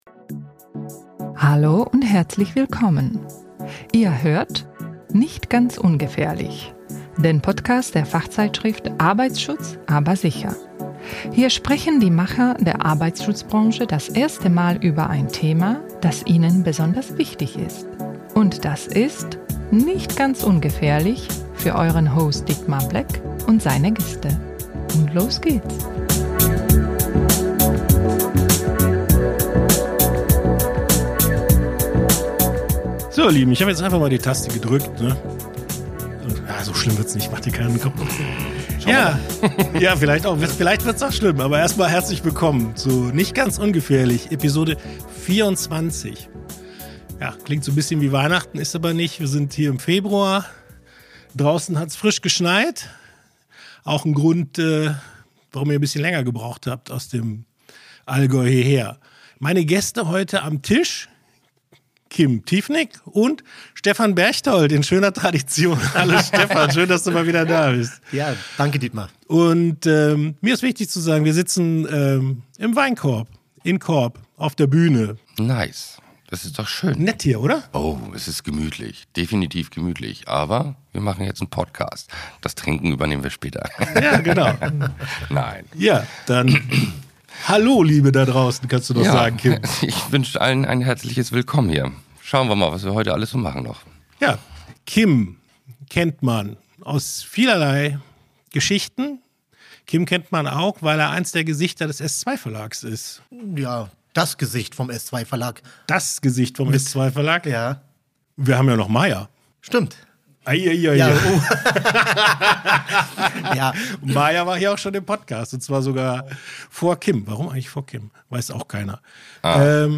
auf der Bühne der Vinothek Weinkorb